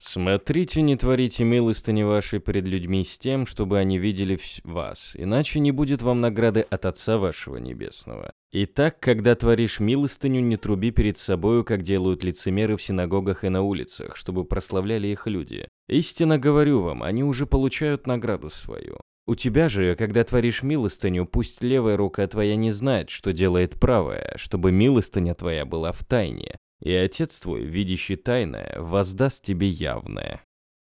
Игровой текст, официальный, информационный-озвучу всё.
AKG P-120, behringer u-phoria umc204